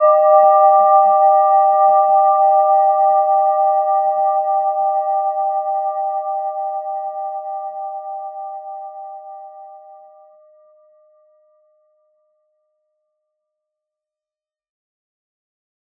Gentle-Metallic-2-G5-p.wav